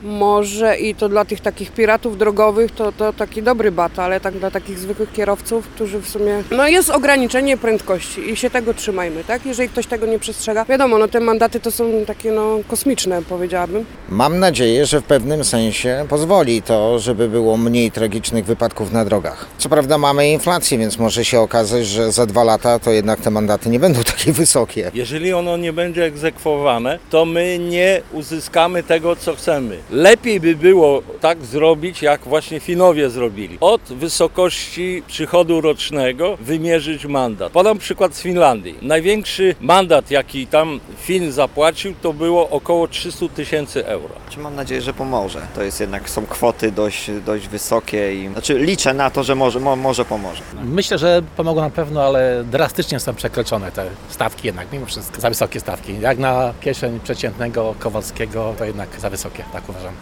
Nowy taryfikator mandatów [SONDA]
– Zapytaliśmy zielonogórzan, co sądzą o nowych taryfikatorach?: